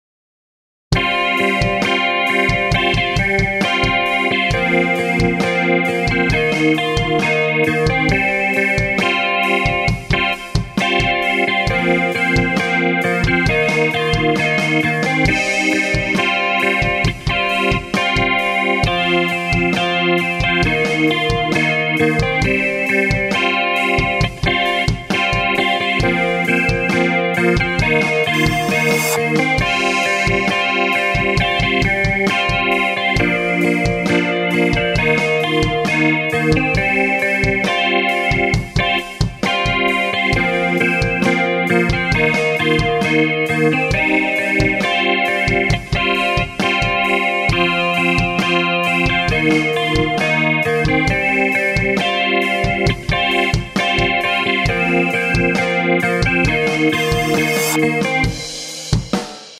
J-Clean